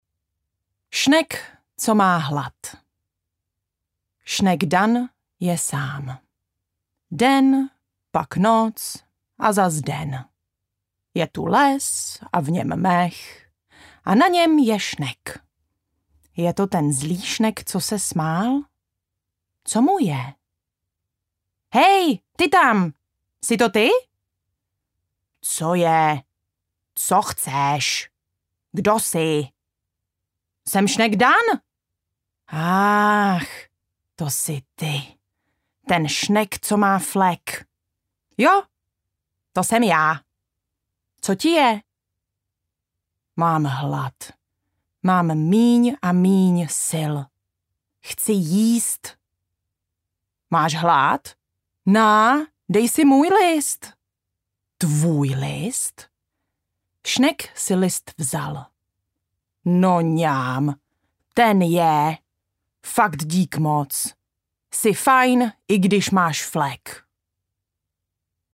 Ten šnek má flek audiokniha
Ukázka z knihy